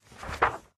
Minecraft Version Minecraft Version latest Latest Release | Latest Snapshot latest / assets / minecraft / sounds / item / book / open_flip1.ogg Compare With Compare With Latest Release | Latest Snapshot
open_flip1.ogg